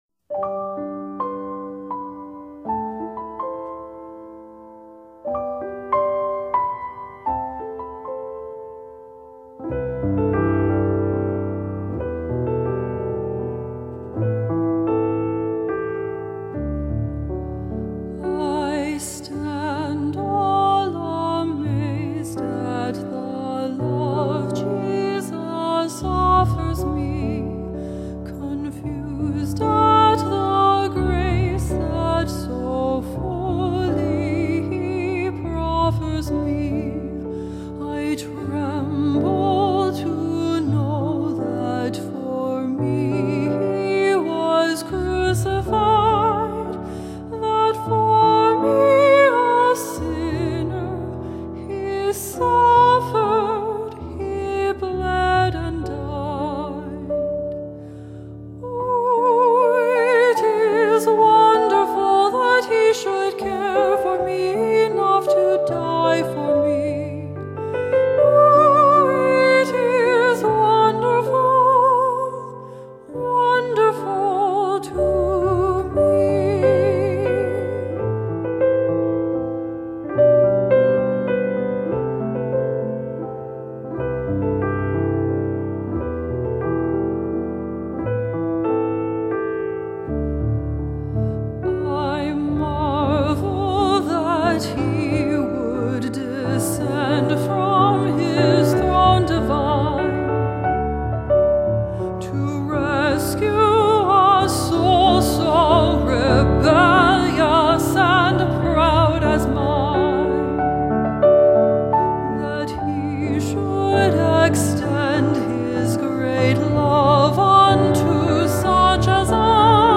Alto and Piano
Hymn arrangement.